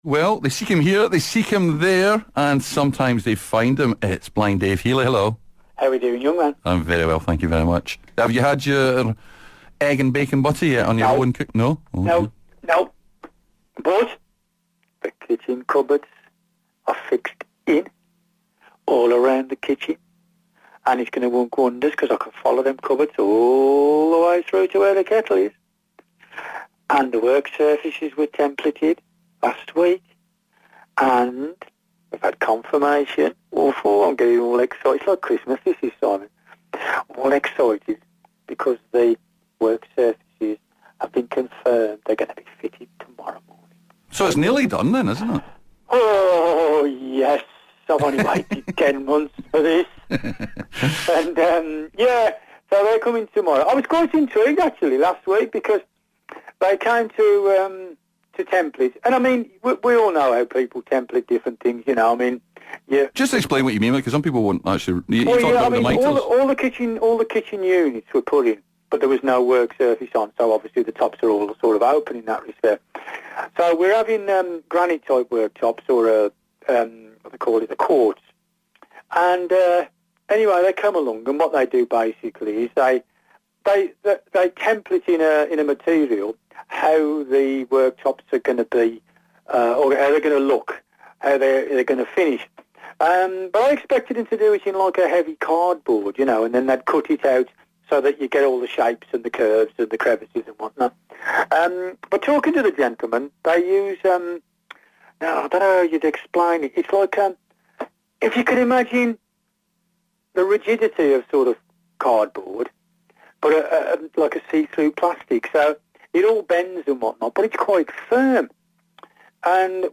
They both share a laugh at how doing DIY with sight loss can make simple things like unpacking boxes difficult.